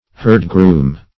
herdgroom - definition of herdgroom - synonyms, pronunciation, spelling from Free Dictionary Search Result for " herdgroom" : The Collaborative International Dictionary of English v.0.48: Herdgroom \Herd"groom`\, n. A herdsman.